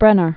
(brĕnər)